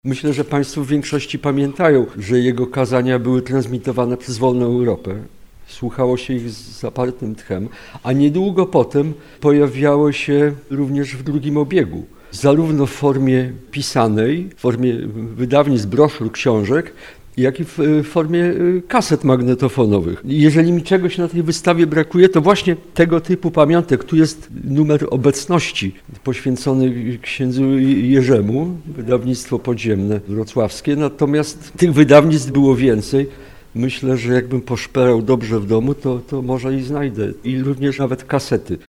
Poseł na Sejm Jacek Świat zaznaczył że, postać księdza Popiełuszki miała ogromne znaczenie w procesie walki o wolność.